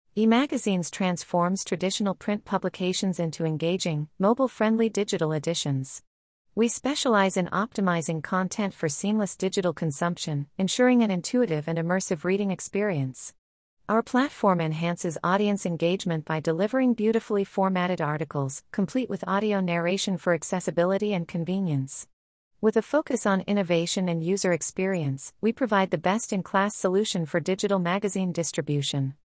We turn text into lifelike speech by leveraging Neural Text-to-Speech systems to create natural-sounding human audio that keeps your subscribers engaged.